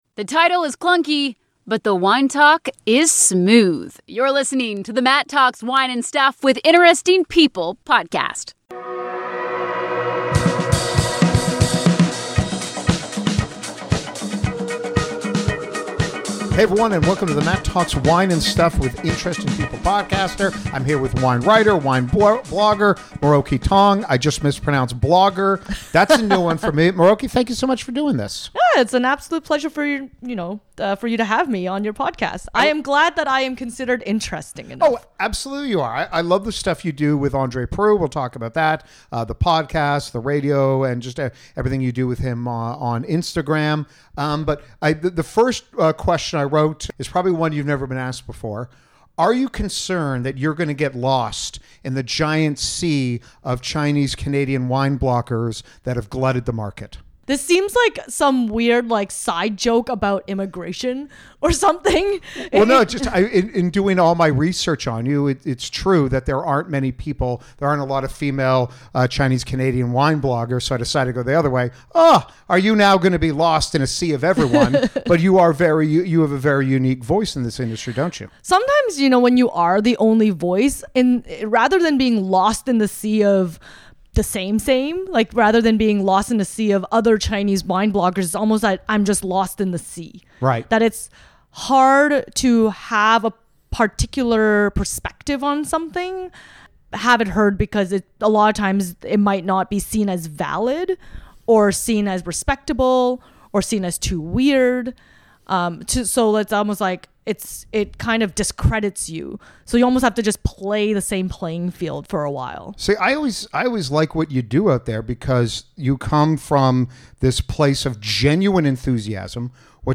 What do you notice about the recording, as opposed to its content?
I admire her crazy work ethic level when it comes to all her projects and the passion she has for all aspects of the wine world. Not a shock at all how much fun our conversation was.